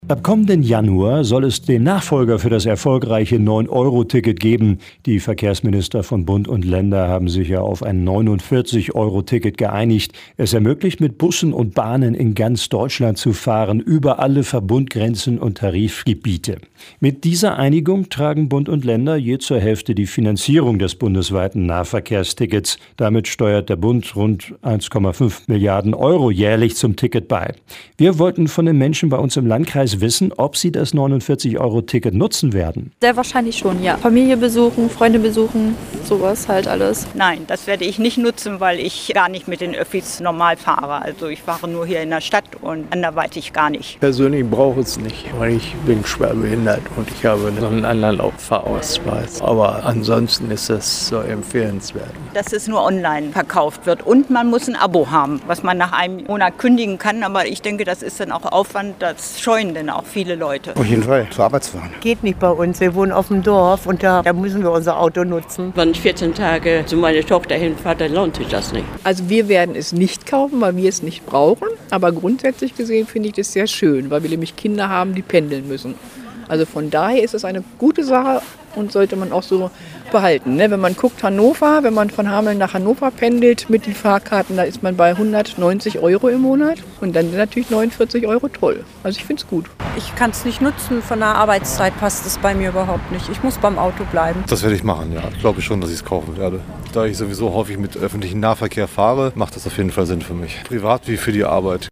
Umfrage zum Deutschlandticket für 49 Euro